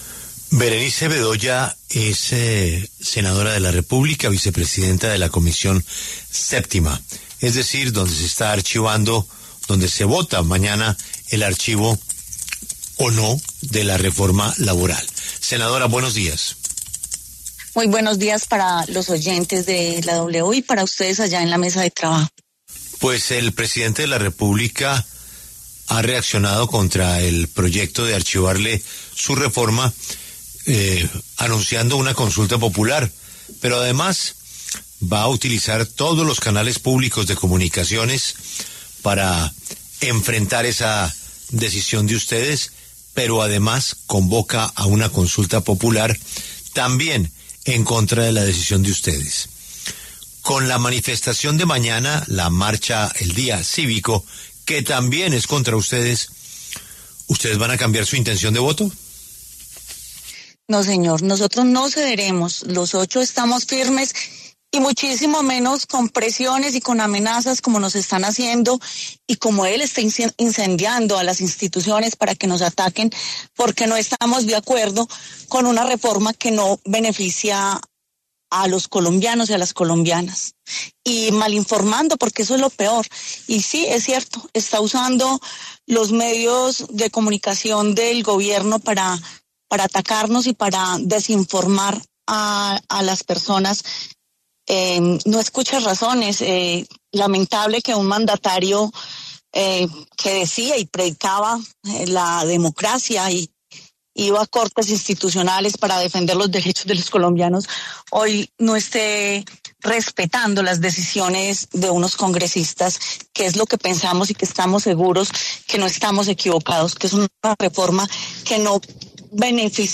A un día de que la Comisión Séptima del Senado vote la ponencia de archivo de la reforma laboral, la senadora Berenice Bedoya, vicepresidenta de la Comisión Séptima del Senado y directora del partido ASI, pasó por los micrófonos de La W.